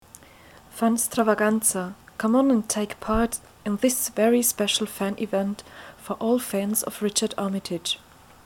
Just listen to this mesmerizing voice beckoning you to be there.
She has a very beautiful voice, and she’s not the only one.